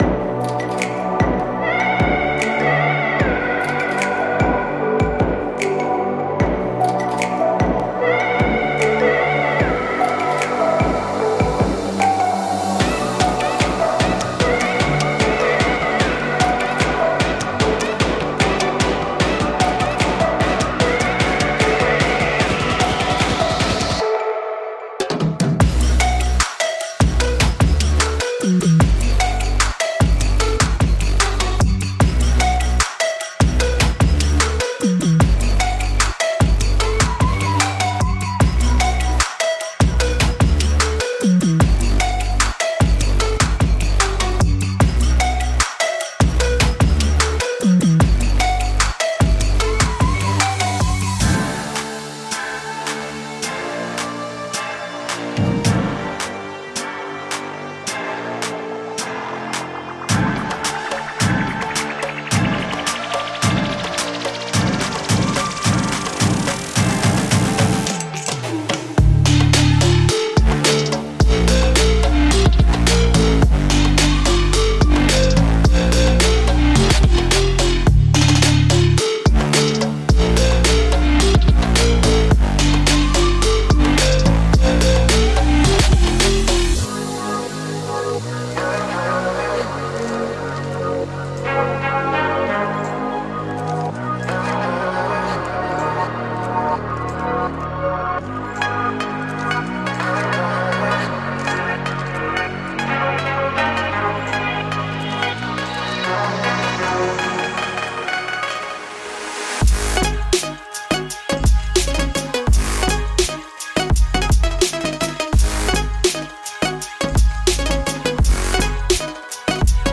3. Future Bass
此产品包充满了丰富，强大的合成器声音。
从坚韧的里斯低音到脸部弹拨和引线，您将在其中找到所有东西。
8真实乐器循环（吉他，钢琴，琴弦）
5人声循环